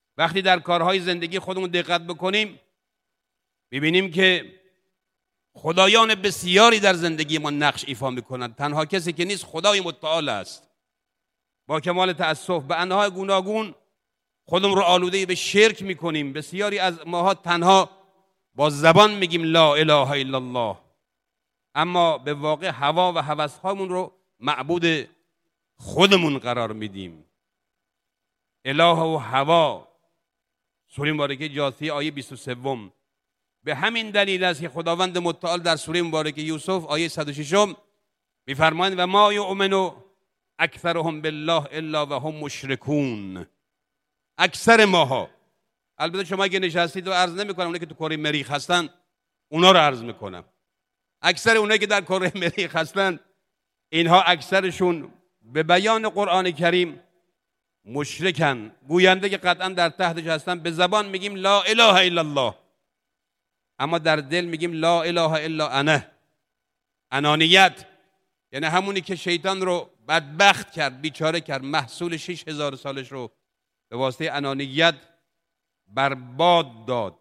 با بررسی خطبه‌ حجت الاسلام موسوی فرد در نماز جمعه گذشته، مشخص شد وی در توضیح آیه ۱۰۶ سوره یوسف درباره شرک به گونه‌ای شرح می‌دهد که به نمازگزاران احترام گذاشته باشد.
امام جمعه اهواز با بین اینکه البته منظورم حاضران نیست با خنده و با حالت مزاح گفت: بله اکثر ساکنان کره مریخ شرک دارند دچار «انانیت» هستیم ؛ همان چیزی که شیطان را بیچاره کرد و عبادت ۶ هزار ساله‌ او را بر باد داد.